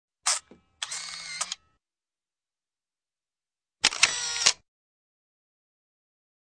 Звук скриншота 3